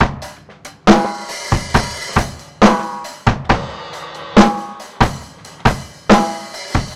C BEAT 2  -L.wav